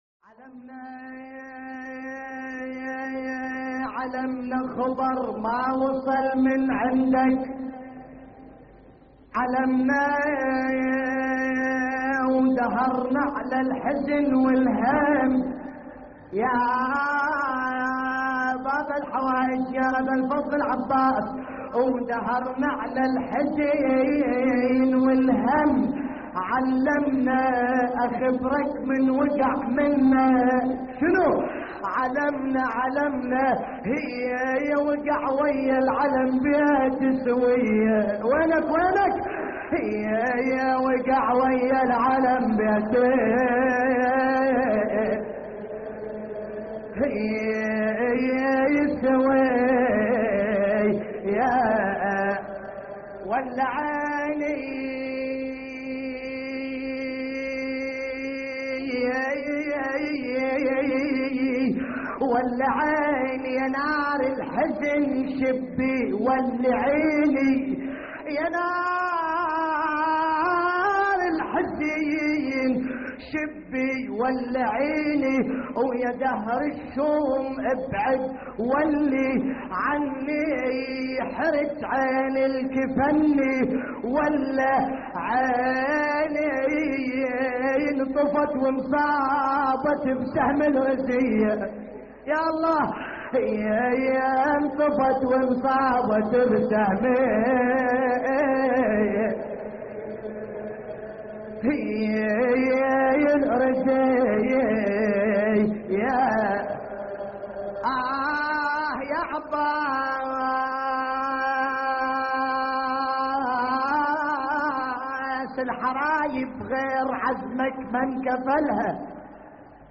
نعي